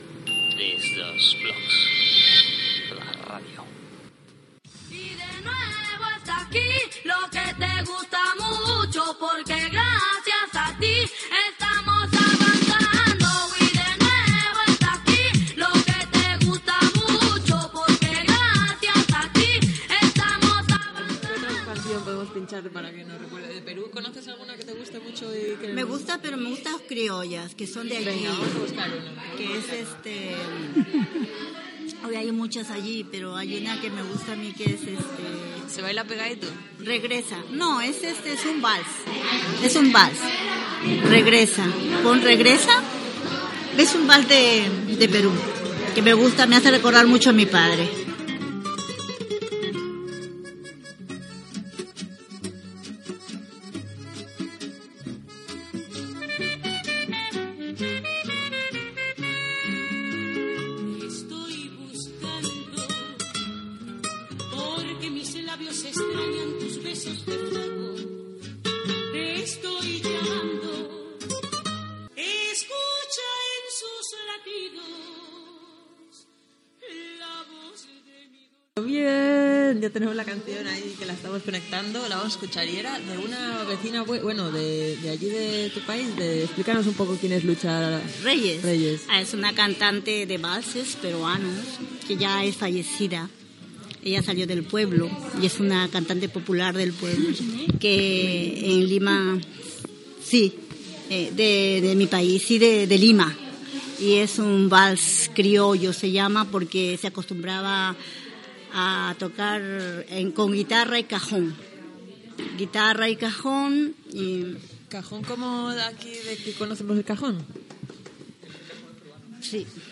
Indicatiu de la ràdio, música peruana, la cantant Lucha Reyes, l'instrument del "cajón"